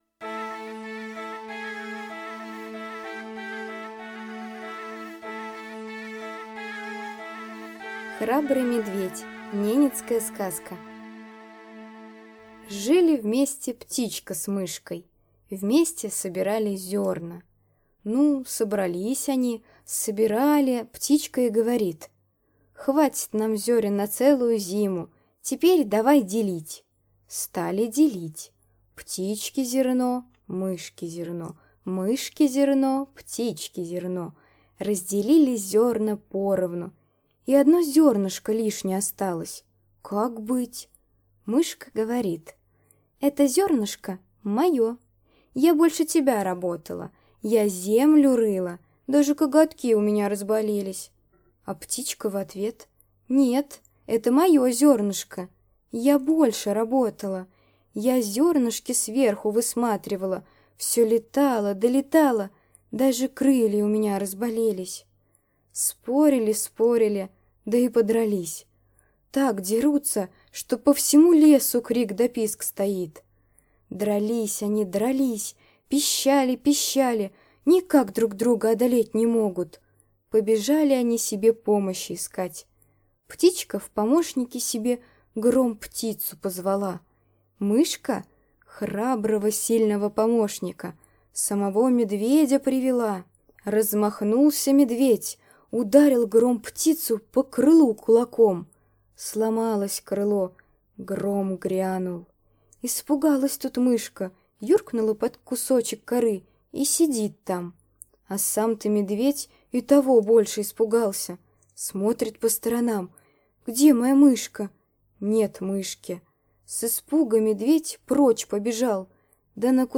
Храбрый медведь - ненецкая аудиосказка.